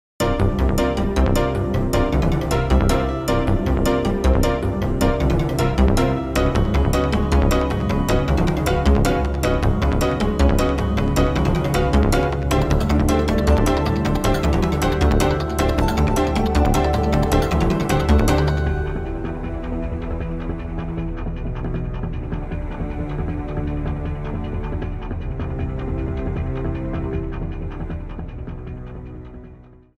Trimmed to 30 seconds, with a fade out effect